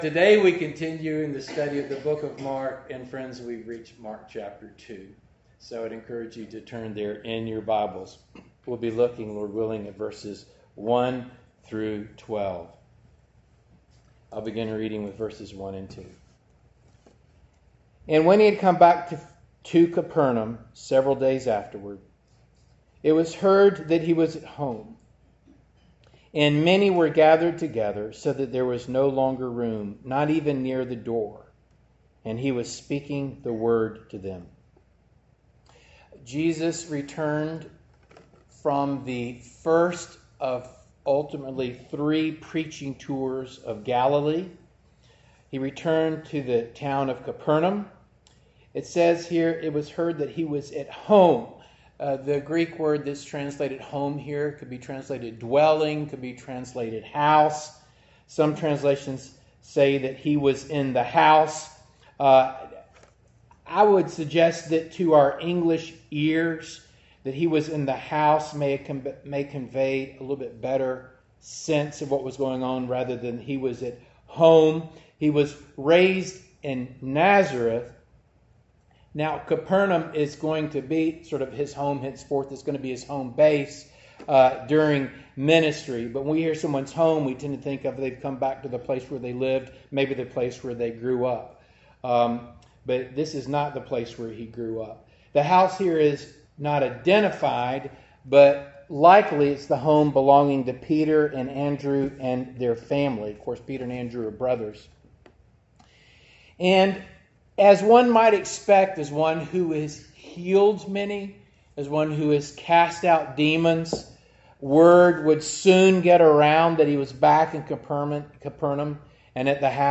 Mark Passage: Mark 2:1-12 Service Type: Morning Service Download Files Bulletin « “‘I Am Willing